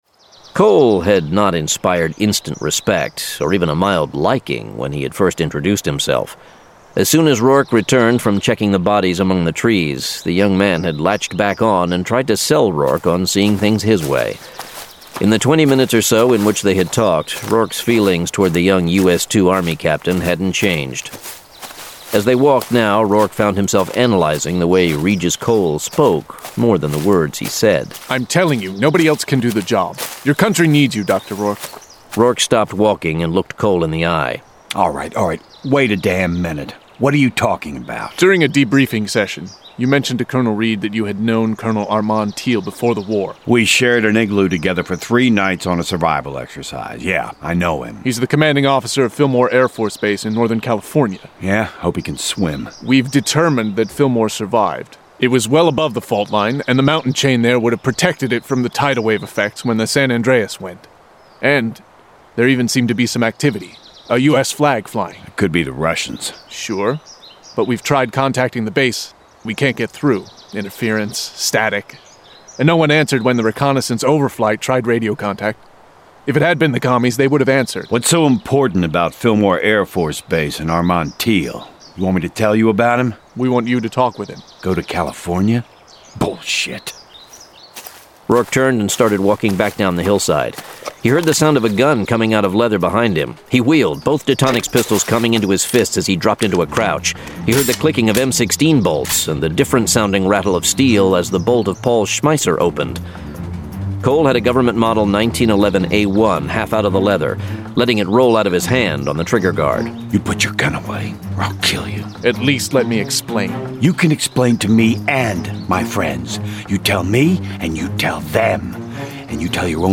Full Cast. Cinematic Music. Sound Effects.
[Dramatized Adaptation]
Genre: Science Fiction